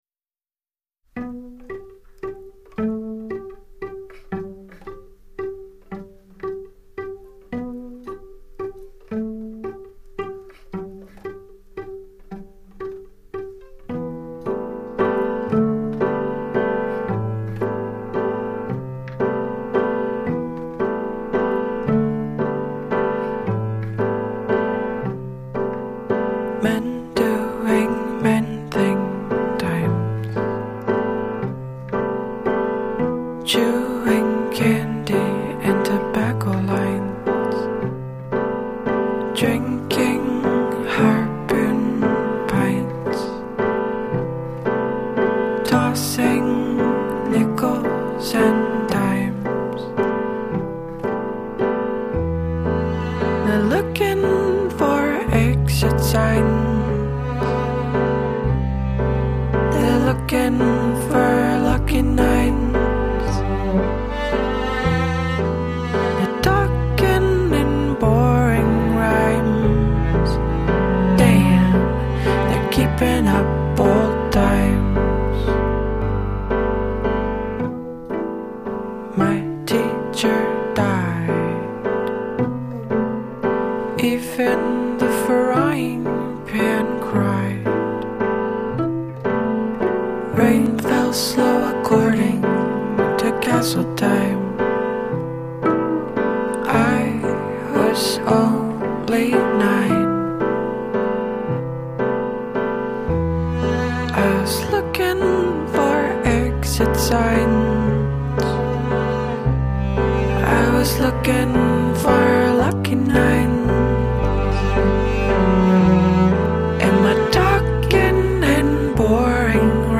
过就在简单之中，给了听众最直接最简洁的触动。